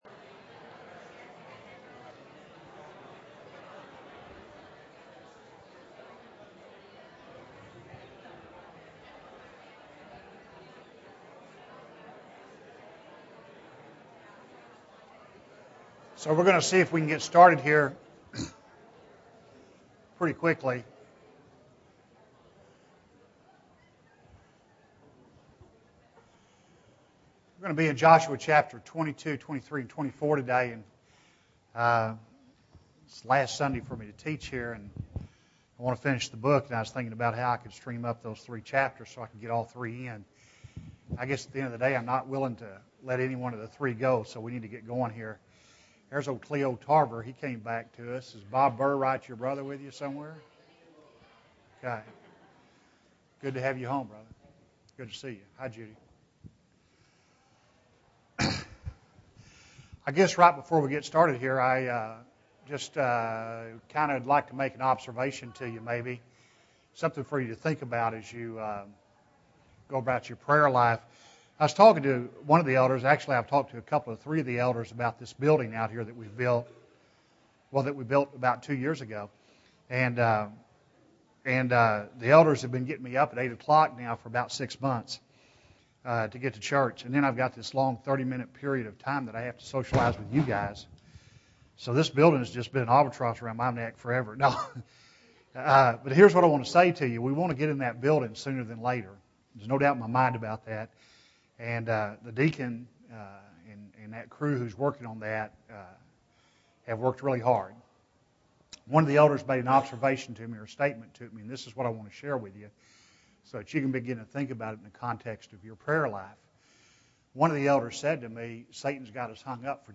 Joshua 22-24 (14 of 14) – Bible Lesson Recording
Sunday AM Bible Class